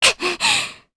Artemia-Vox_Damage_jp_01.wav